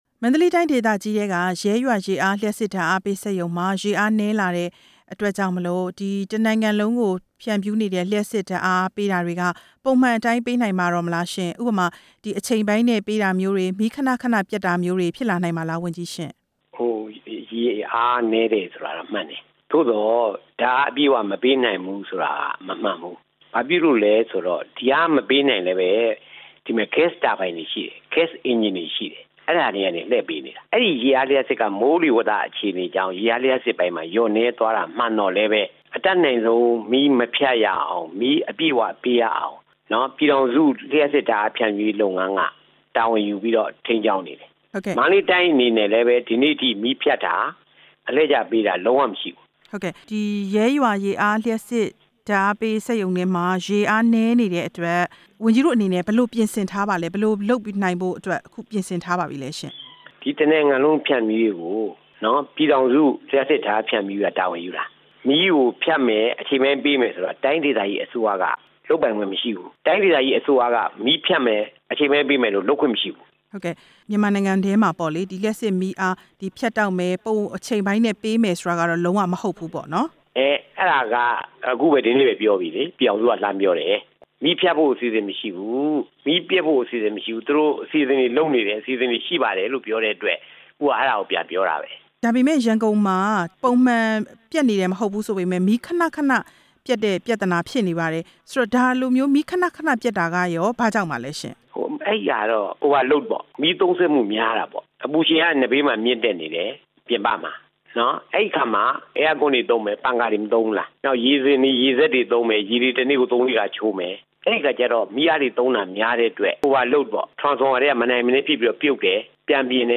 လျှပ်စစ်နဲ့ စက်မှုလက်မှုဝန်ကြီးဌာန ဝန်ကြီး ဦးကျော်မြင့်နဲ့ မေးမြန်းချက်